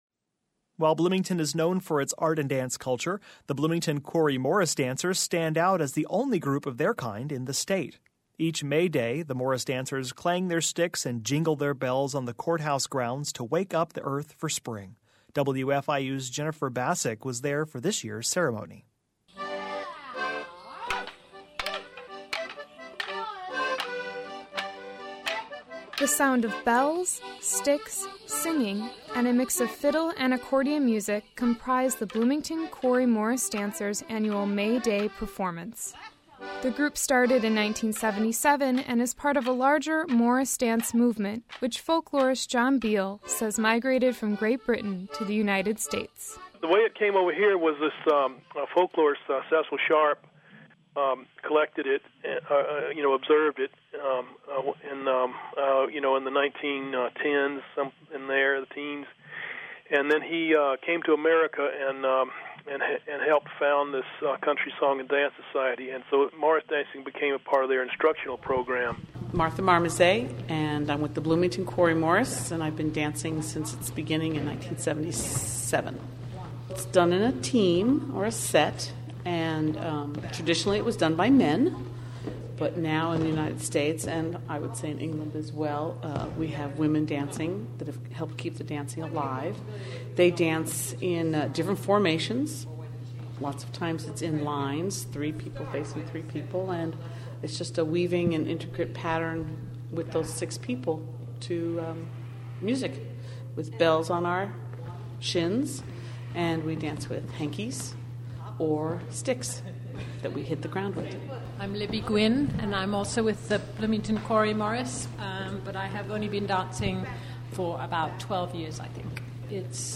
Listen in Popup Download MP3 Comment The sound of bells, sticks, singing and a mix of fiddle and accordion music comprise the Bloomington Quarry Morris dancers annual May Day performance.